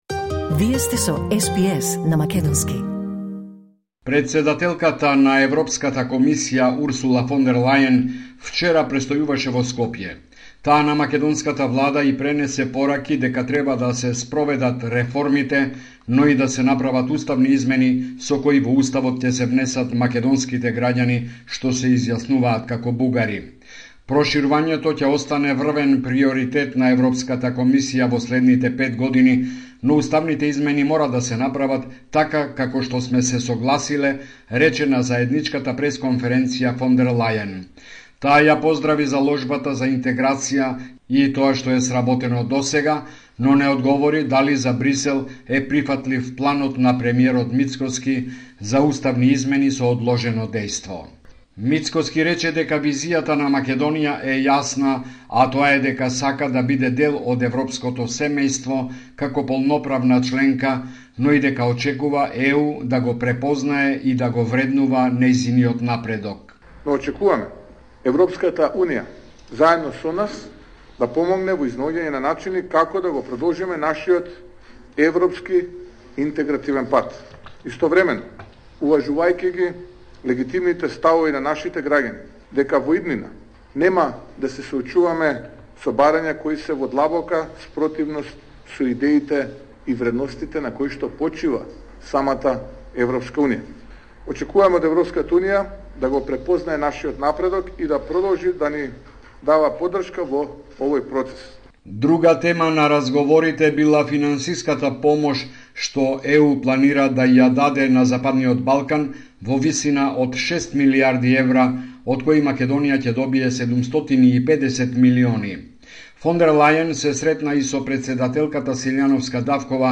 Homeland Report in Macedonian 25 October 2024